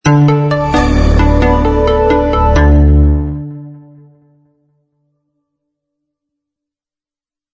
44.1 kHz 明亮简洁片头音乐 全站素材均从网上搜集而来，仅限于学习交流。